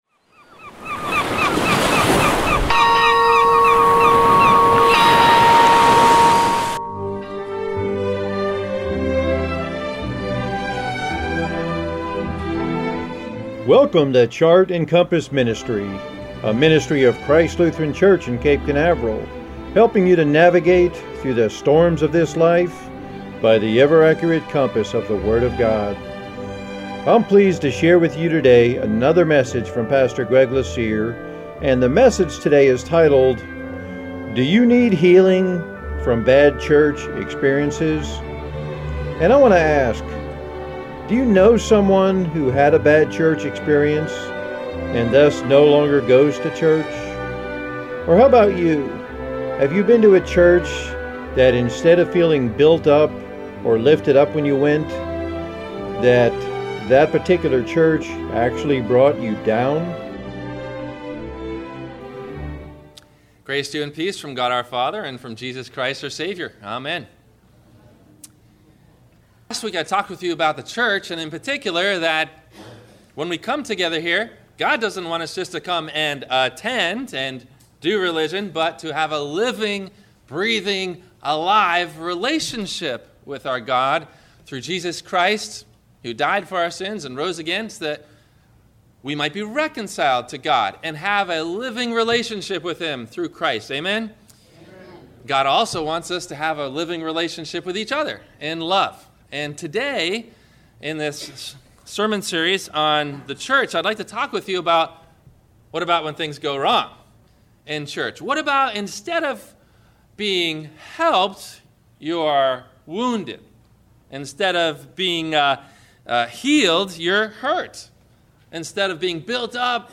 Do You Need Healing from Bad Church Experiences? – WMIE Radio Sermon – February 29 2016